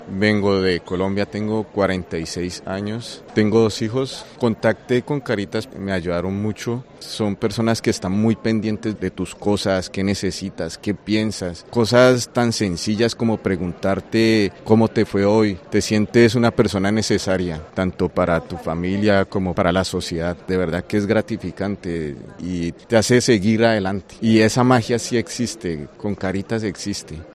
Varios usuarios atendidos por Cáritas cuentan durante el acto fallero su experiencia y como la ONG de la Iglesia les ha cambiado la vida